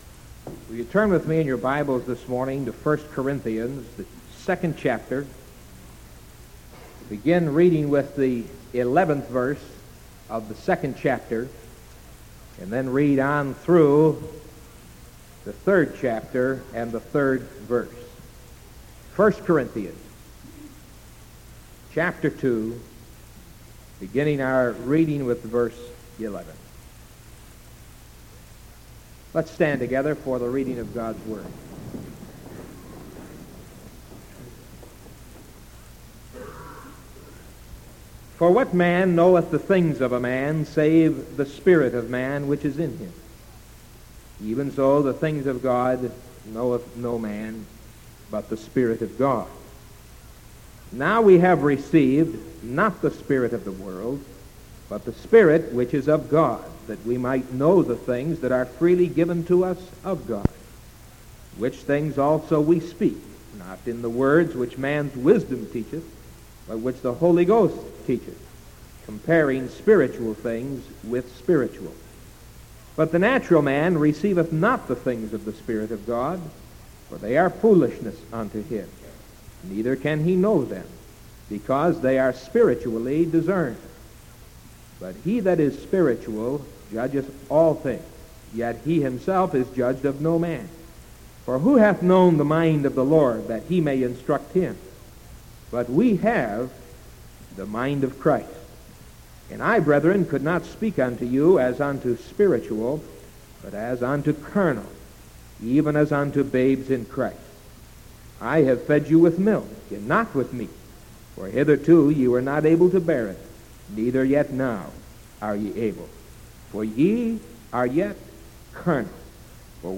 Sermon November 25th 1973 AM